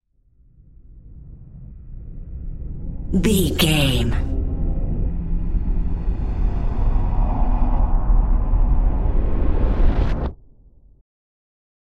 Sound Effects
Atonal
Slow
scary
tension
ominous
dark
eerie
strings
piano
synthesiser
ambience
pads